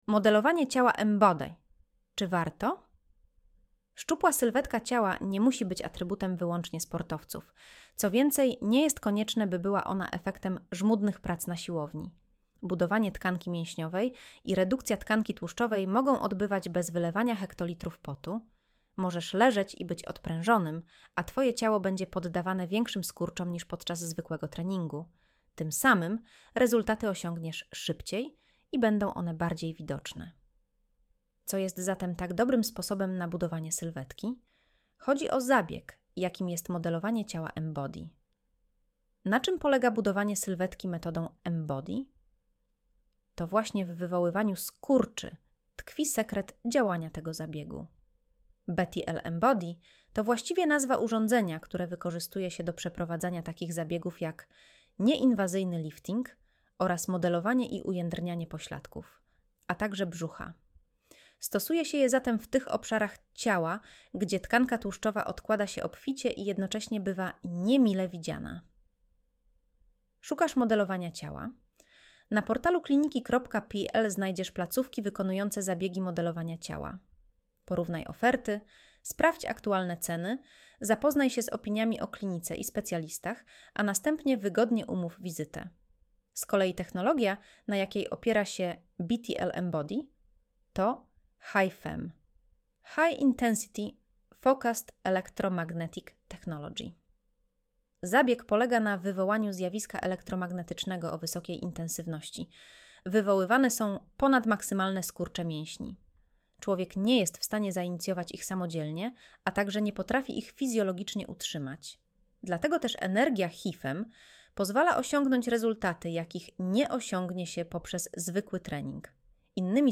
Streść artykuł Słuchaj artykułu Audio wygenerowane przez AI, może zawierać błędy 00:00